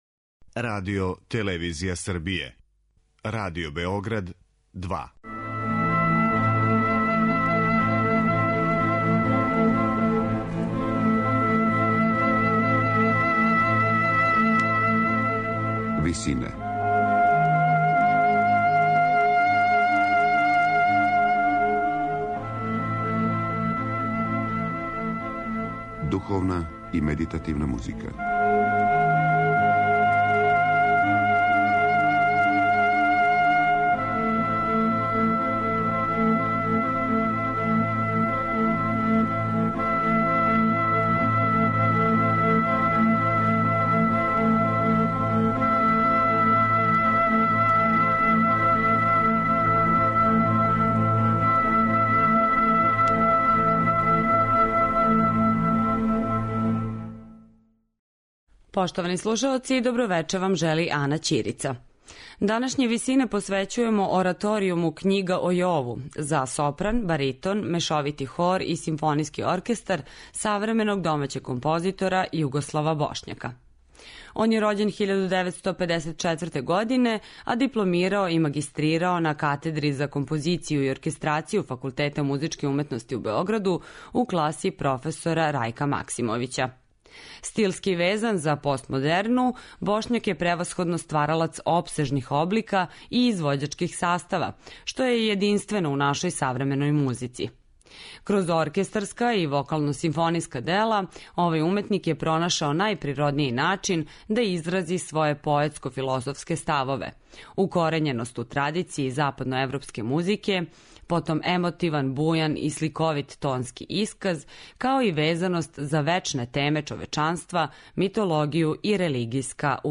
духовне и медитативне музике
сопран, баритон, мешовити хор и симфонијскии оркестар